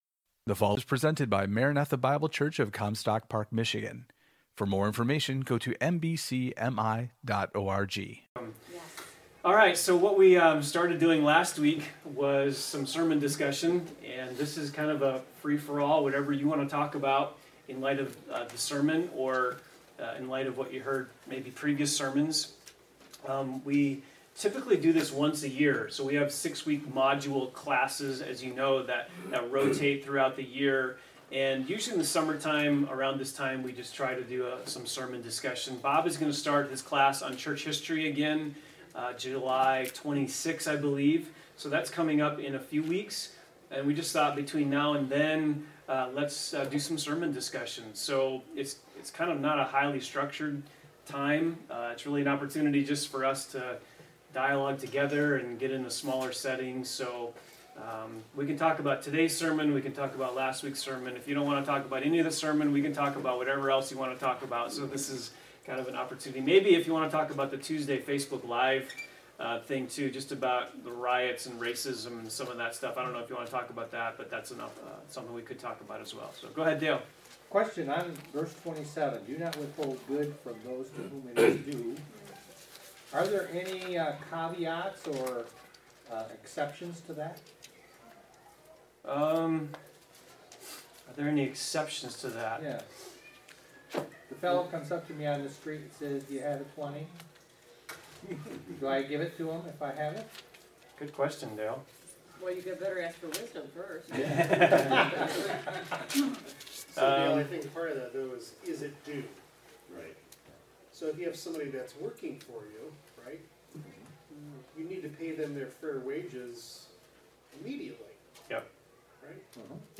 Equipping Hour – Sermon Discussion